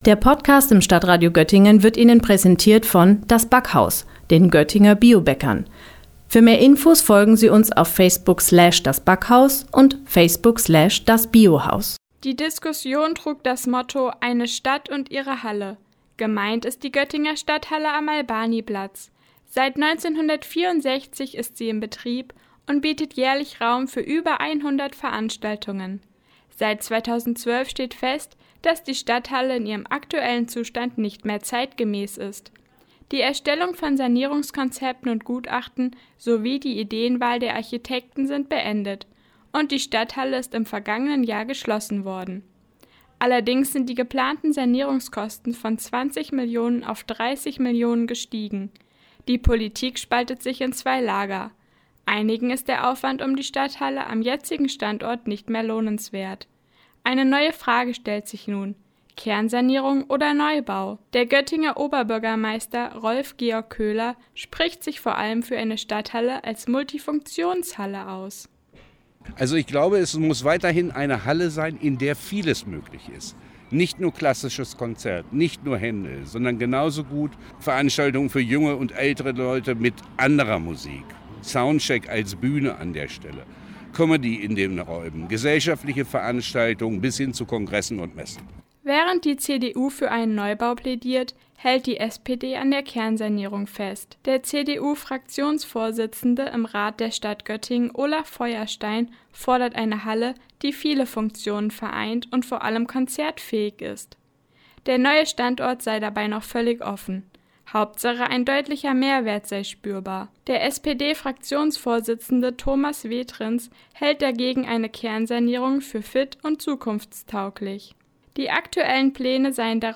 Beiträge > Podiumsdiskussion „Eine Stadt und ihre Halle“ - StadtRadio Göttingen